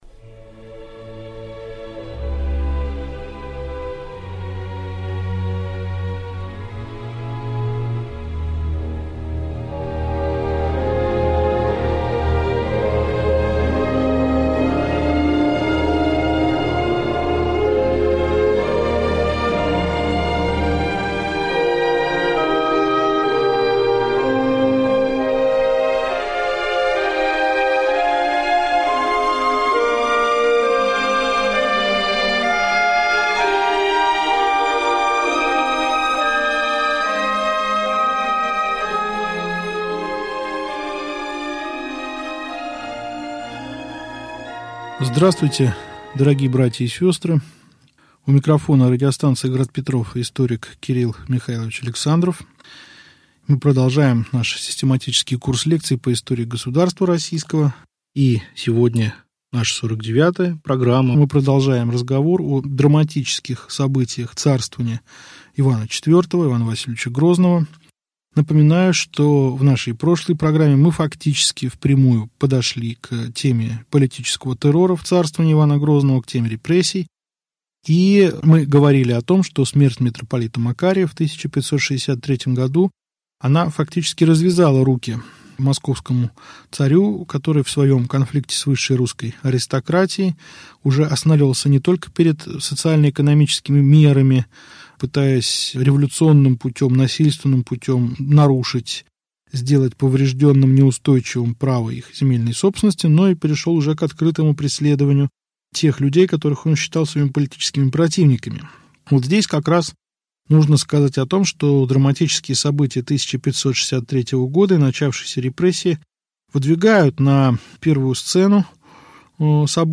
Аудиокнига Лекция 49. Правление Ивана Грозного. Отъезд Курбского | Библиотека аудиокниг